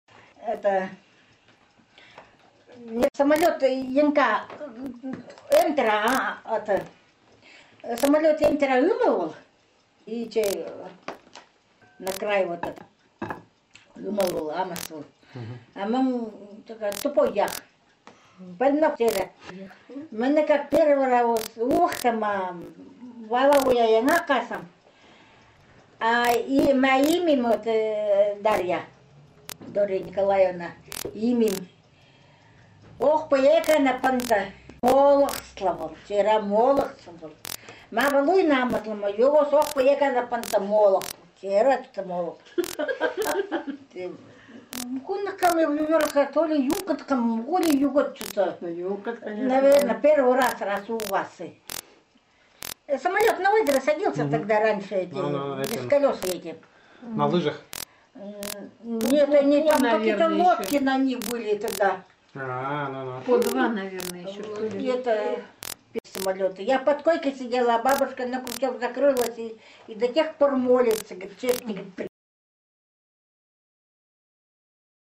These Eastern Khanty texts were recorded in 2007 in the upper and lower Vasyugan river areas, and in the Alexandrovo Ob’ river communities. The texts were narrated by the male and female Vasyugan Khanty and Alexandrovo Khanty speakers to other Khanty speakers and to the researchers, who also spoke limited Khanty and offered occasional interjections to the narration.